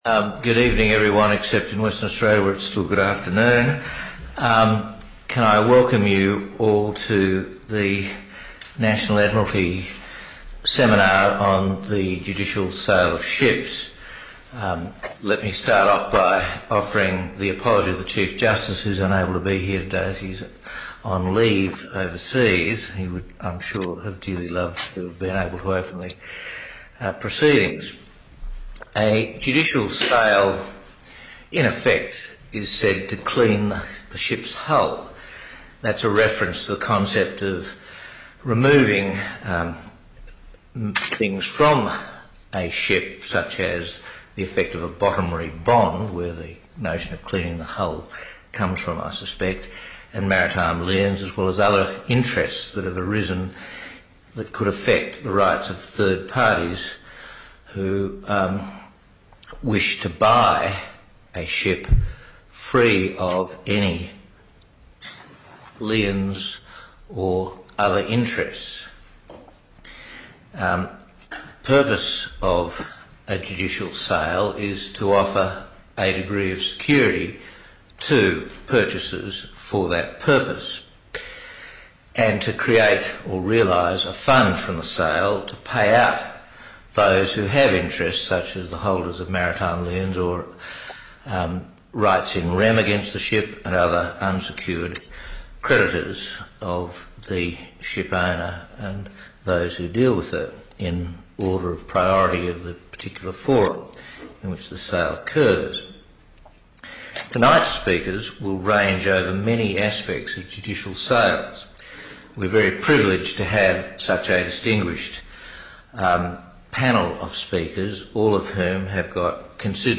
All presentations from the Admiralty Seminar 2017